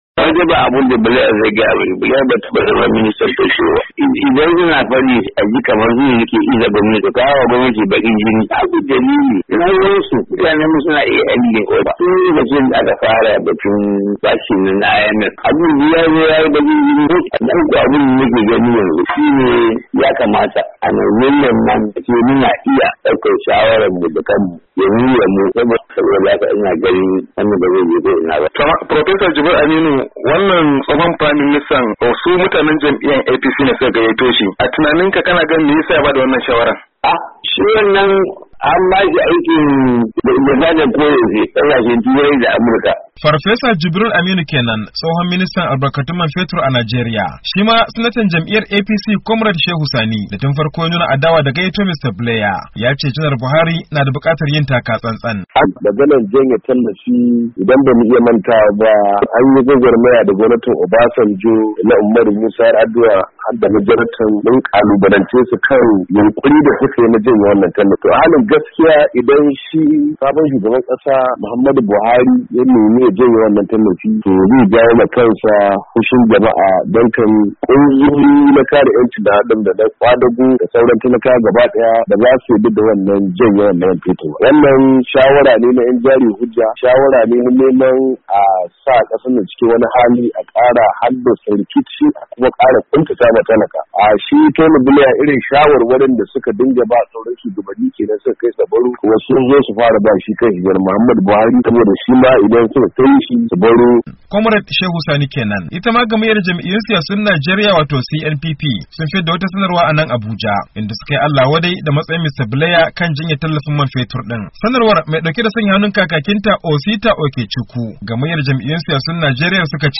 Ga ci gaban rahoton.